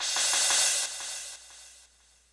crash01.wav